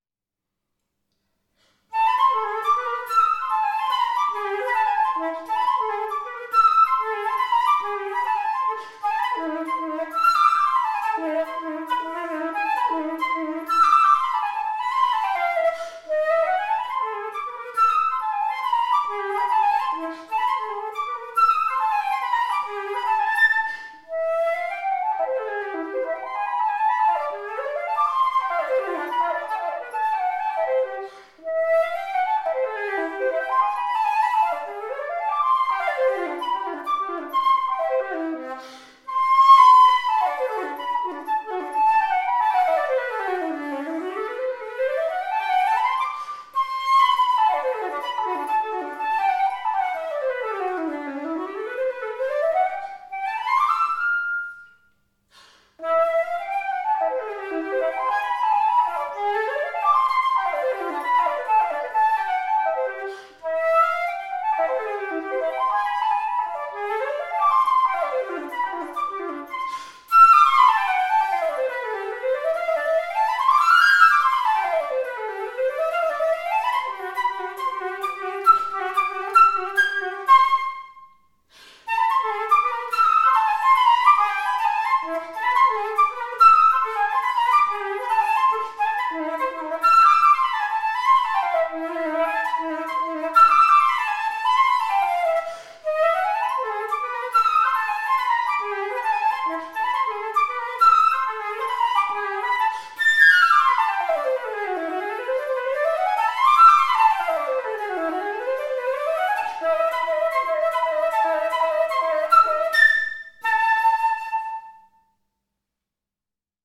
Solid silver Louis Lot Barat era flute
This is the Maison Lot’s model 5, solid silver C-foot flute.
It plays at a=440 Hz fully inserted.  It’s a lovey player in near perfect condition, offering a rich, warm, clear and magically French sound.